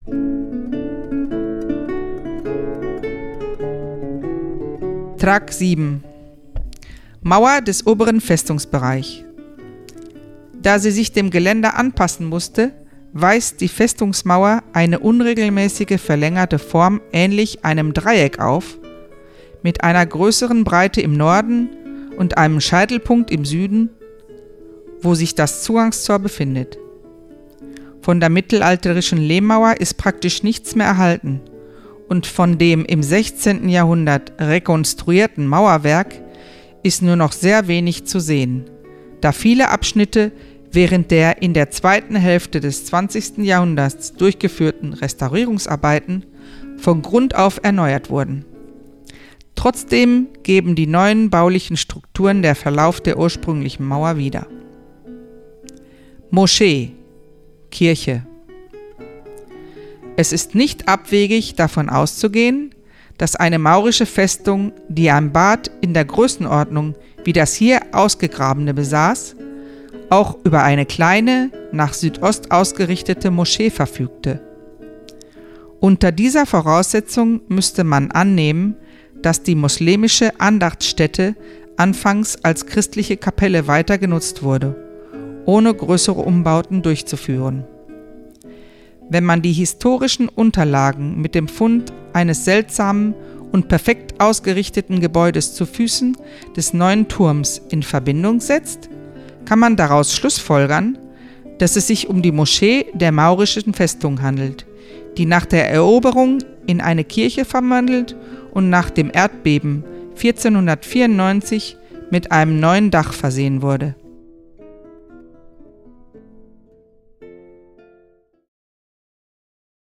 Burg von Salobreña, Besichtigung mit Audioguide